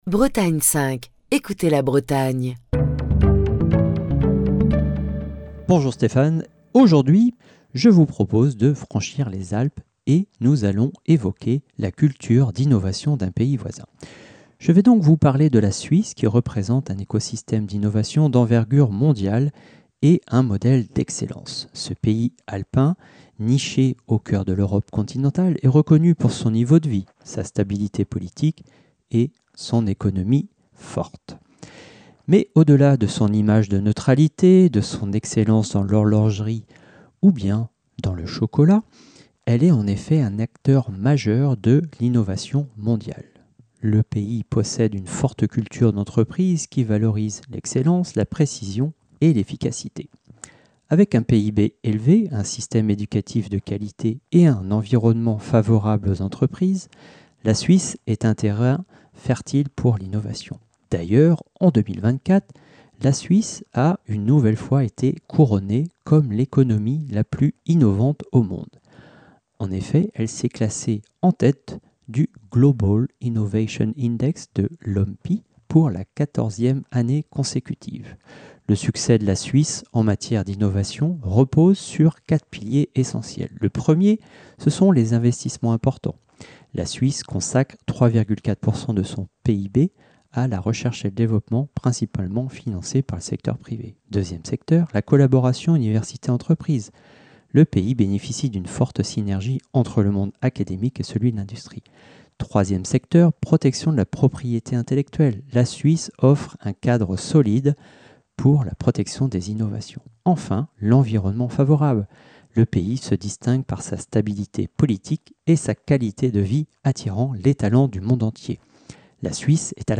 Chronique du 12 février 2025.